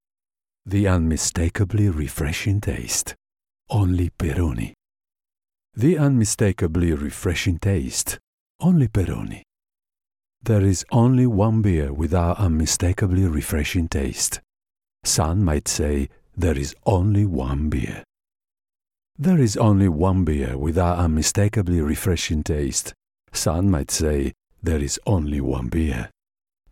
Italian - Male
Great versatility, from deep, warm and velvety to high pitched cartoon warbles from sexy to dark and scary.
Commercial, Bright, Upbeat, smooth